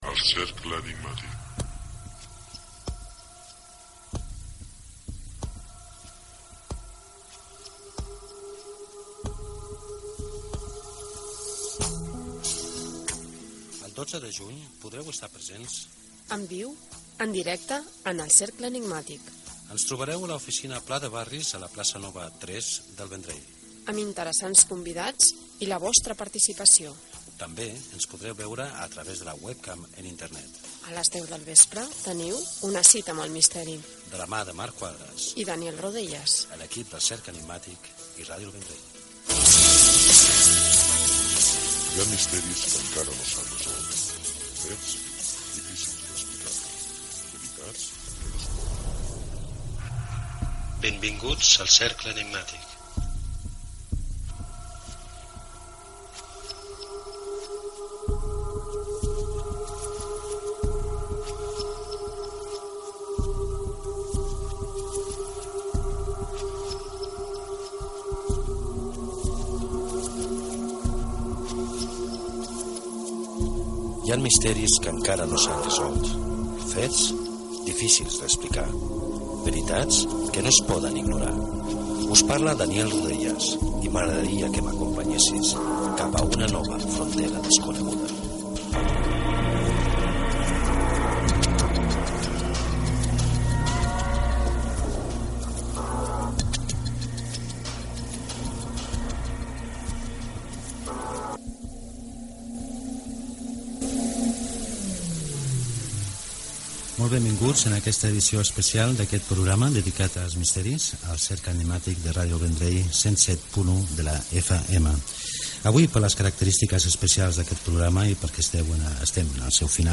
Careta, programa cara al públic, presentació de l'especial "Catalunya i els seus misteris"
Divulgació